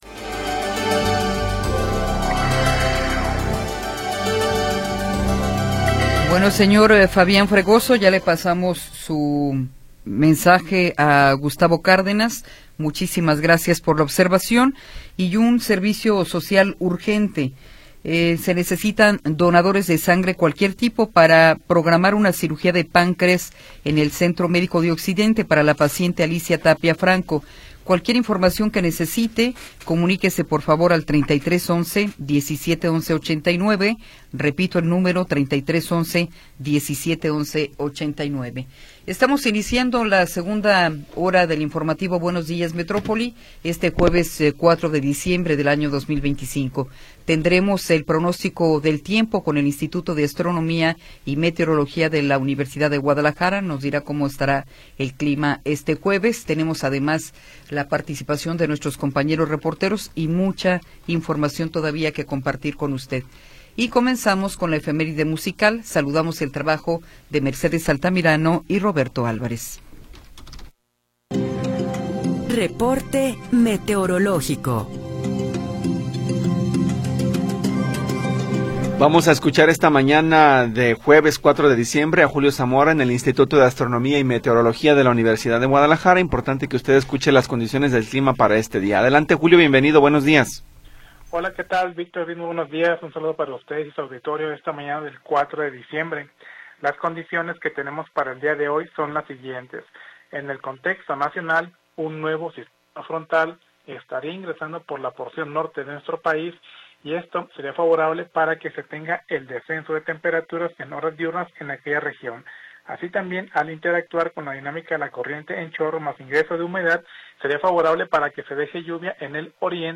Segunda hora del programa transmitido el 4 de Diciembre de 2025.